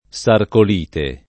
[ S arkol & te ]